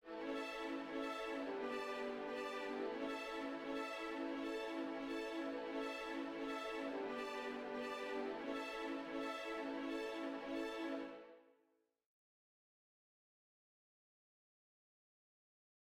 Au chiffre 3 également, des bariolages joués par les violons 2 et les altos, sur l’accord de la majeur, (5eme de ré bien entendu), en balancement avec un accord de sol avec 9eme ajoutée.
bariolages-1.mp3